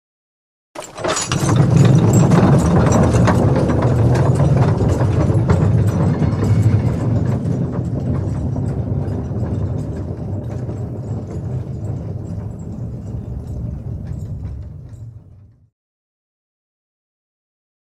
Звуки вагонетки
Звук вагонетки начинает везти пони